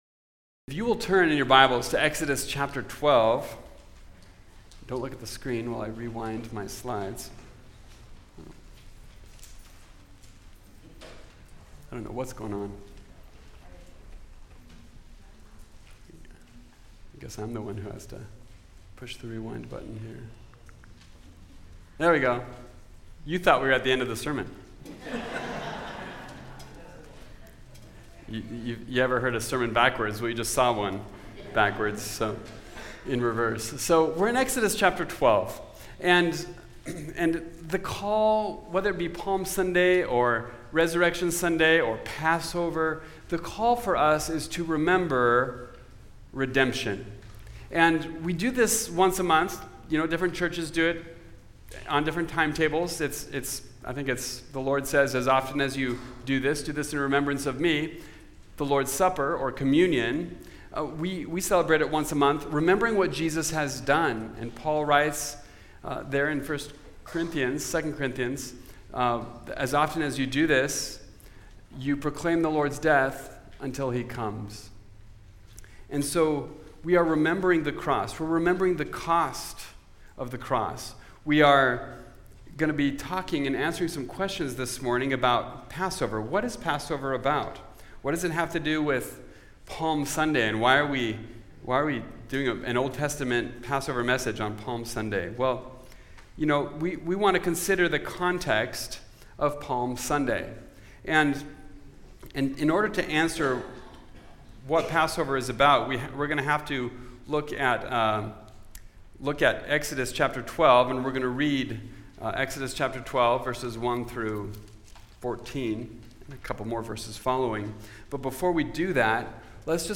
Series: Topical Message